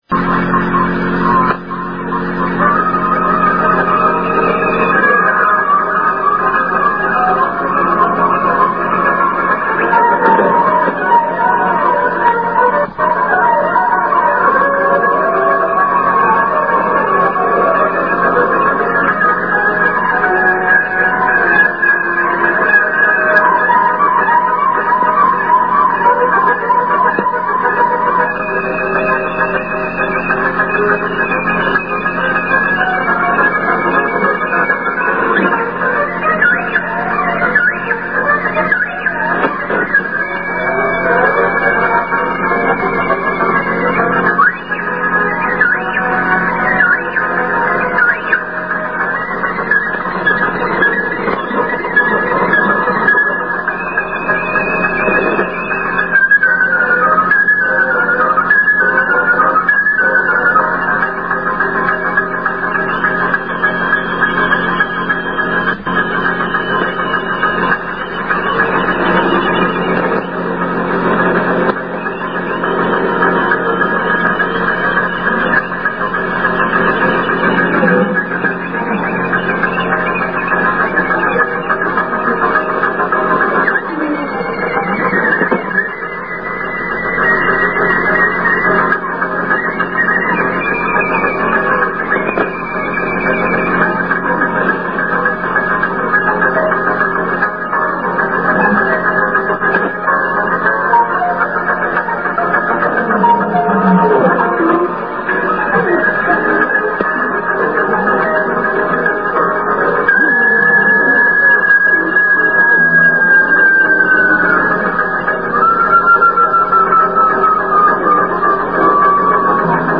Начало » Записи » Номерные станции
V1 "Romanian Skylark" передача сообщения "Румынским жаворонком"